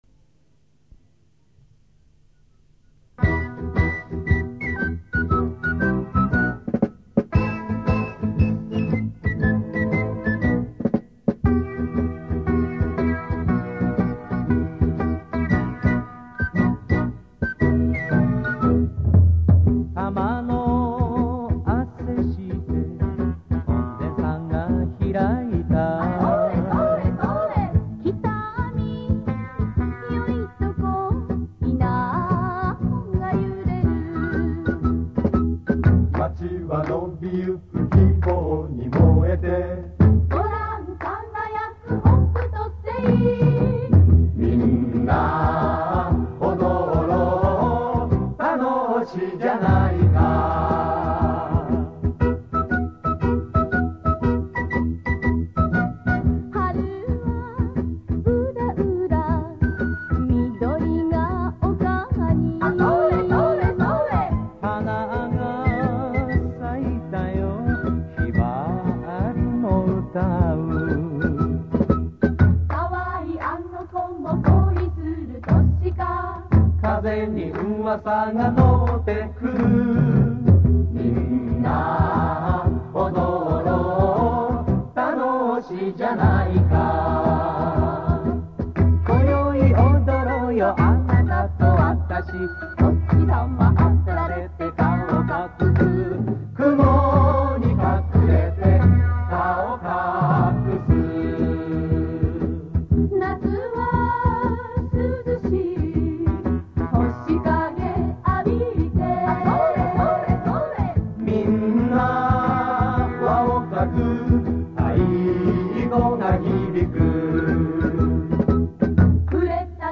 北見囃子（北見市内の盆踊りで使われる歌）